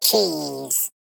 Sfx_tool_spypenguin_vo_take_picture_02.ogg